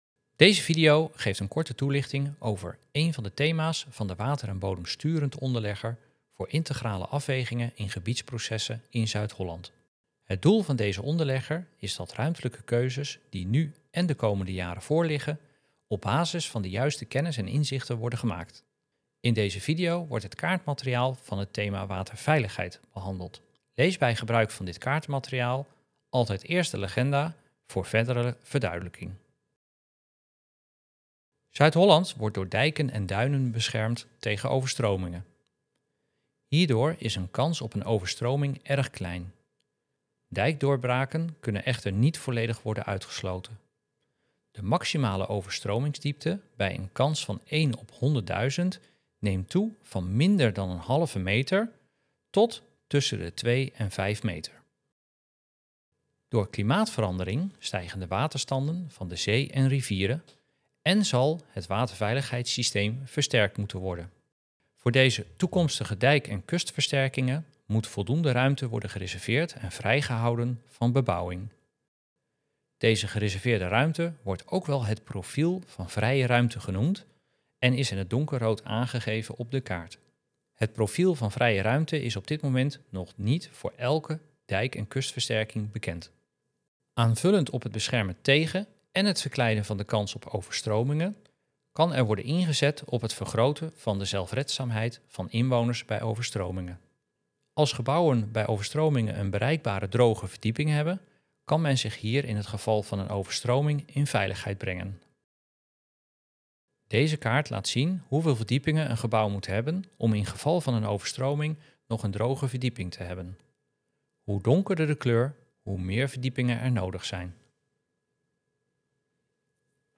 provincie_zuid-holland_animatie-1_waterveiligheid_voice-over.mp3